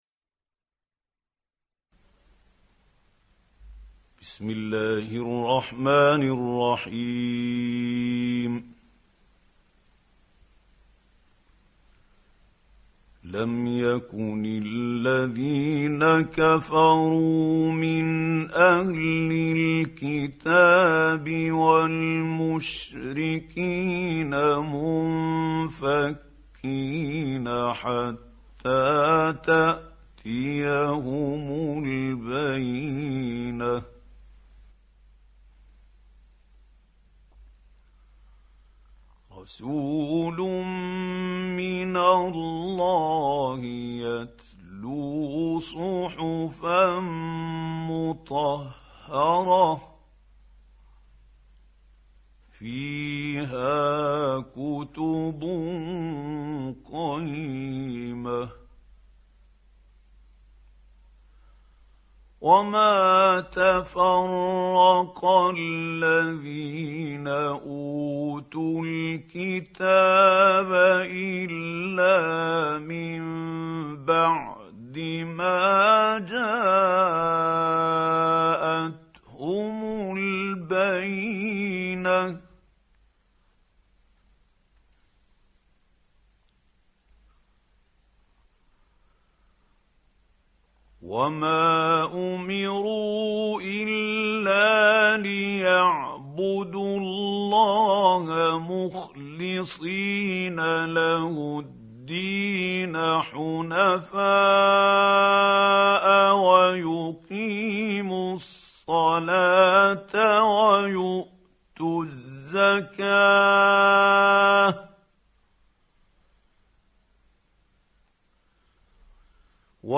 Қуръони карим тиловати, Қорилар.